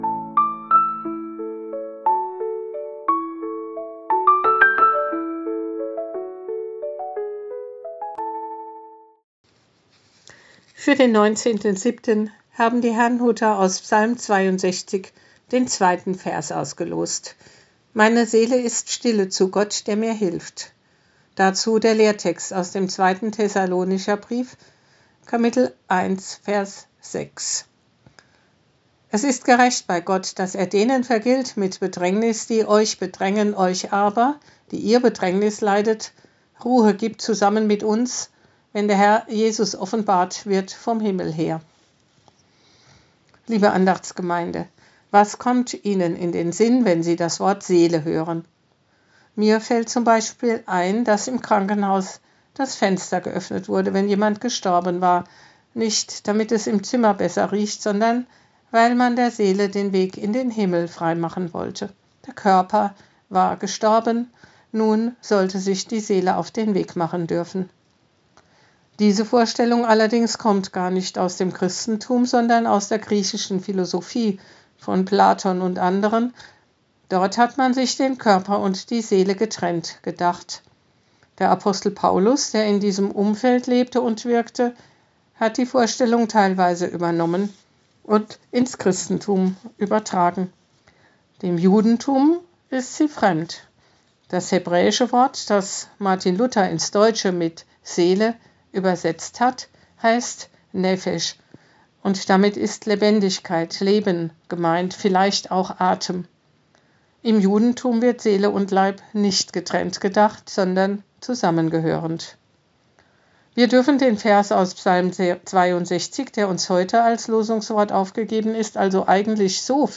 Losungsandacht für Samstag, 19.07.2025 – Prot.